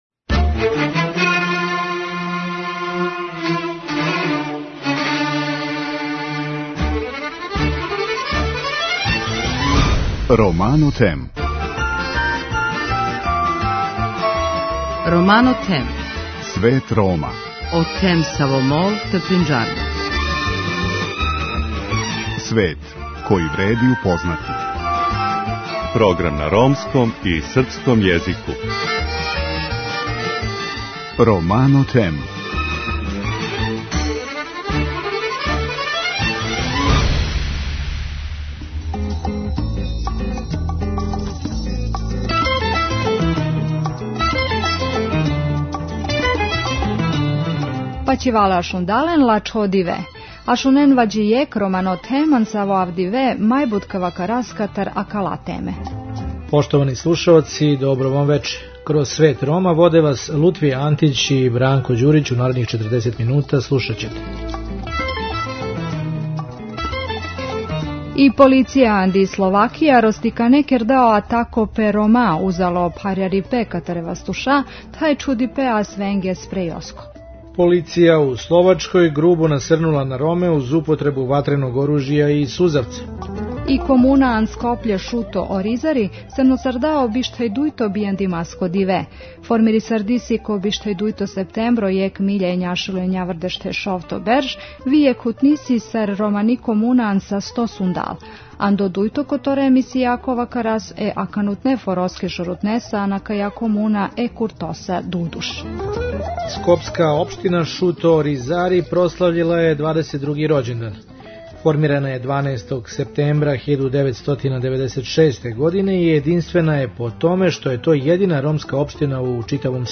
У другом делу емисије разговарамо са актуелним градоначелником ове општине, Куртом Дудушем. Емитујемо прилог о Маријану Бенешу који је недавно сахрањен на бањалулком гробљу уз звуке југословенске химне.